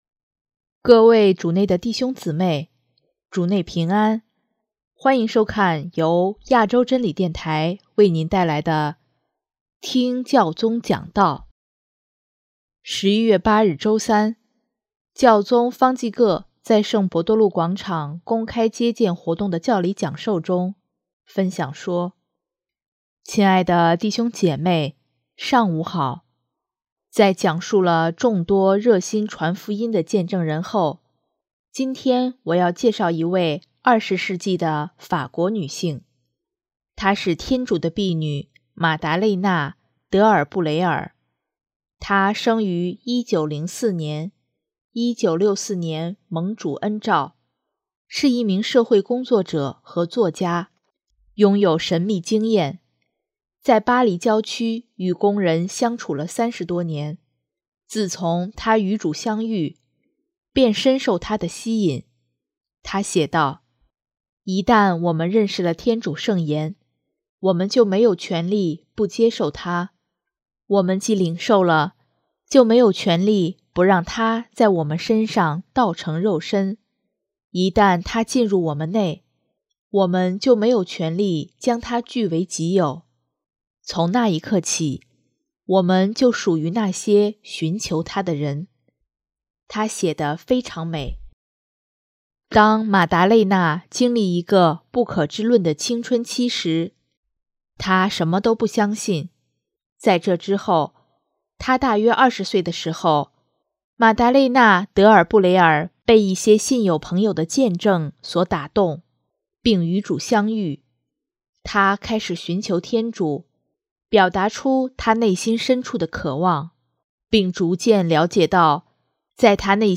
【听教宗讲道】|在爱的动力中取得平衡
11月8日周三，教宗方济各在圣伯多禄广场公开接见活动的教理讲授中，分享说：